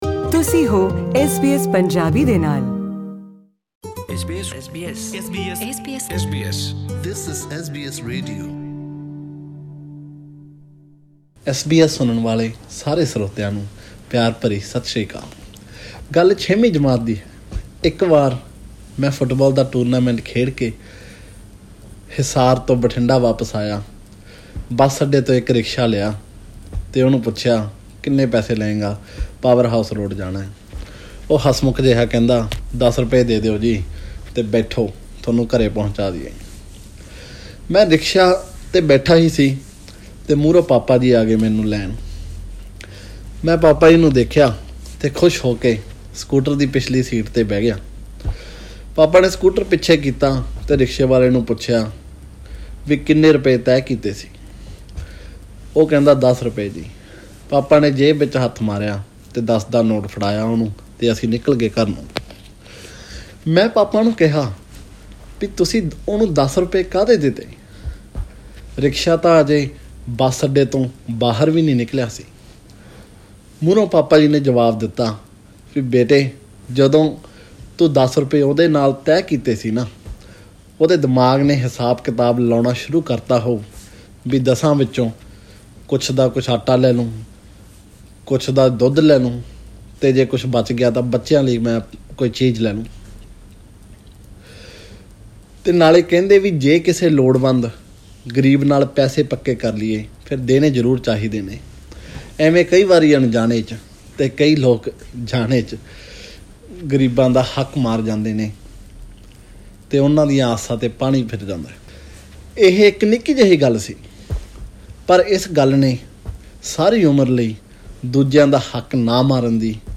at SBS studios in Melbourne